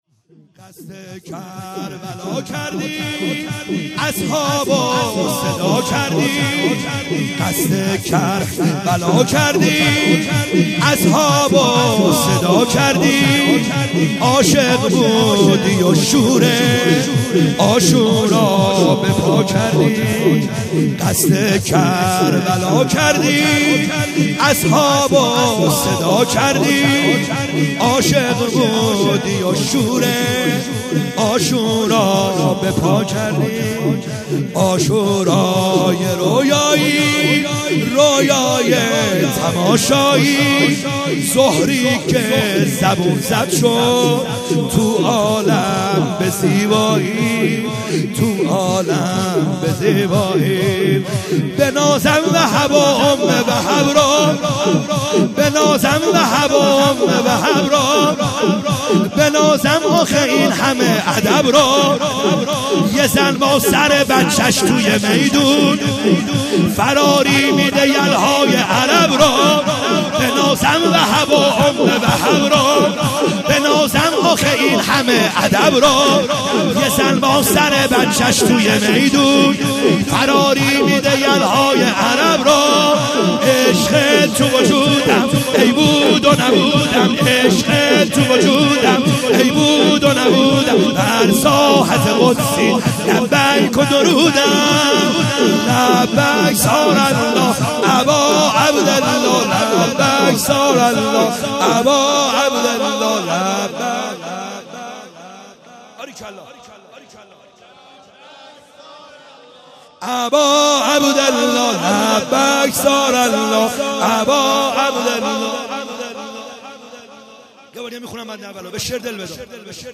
خیمه گاه - بیرق معظم محبین حضرت صاحب الزمان(عج) - شور | قصد کربلا کردی